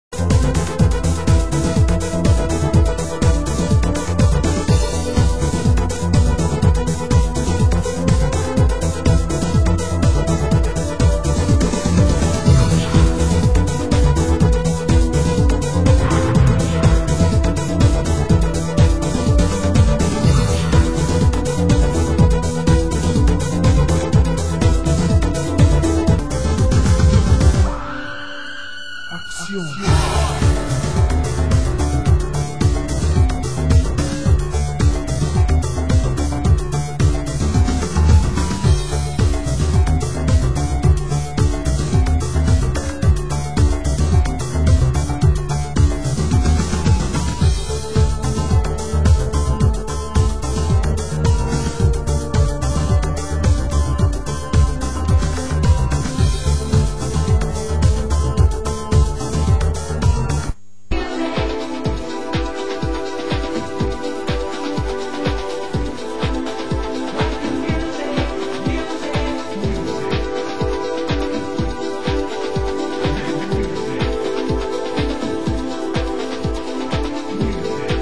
Genre: Euro Rave (1990-92)